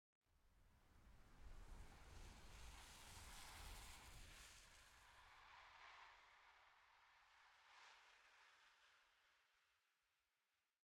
1.21.5 / assets / minecraft / sounds / block / sand / sand3.ogg
sand3.ogg